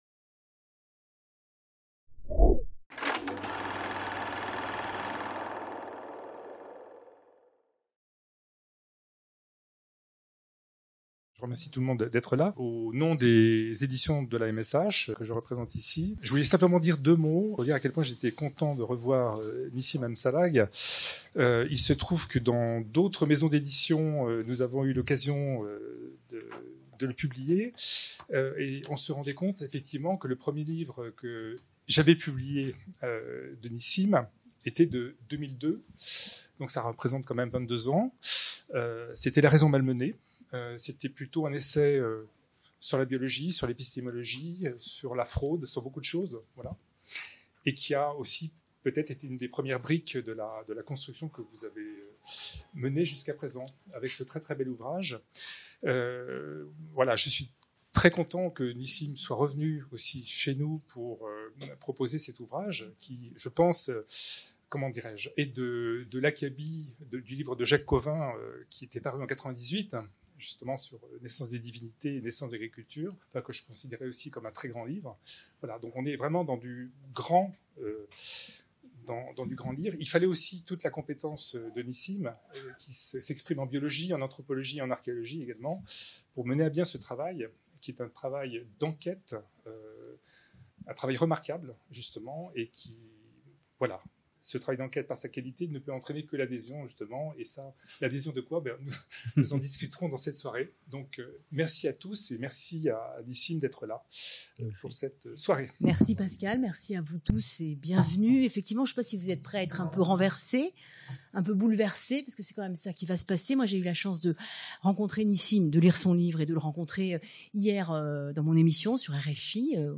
Soirée de présentation de l'ouvrage "Les graines de l'Au-delà" | Canal U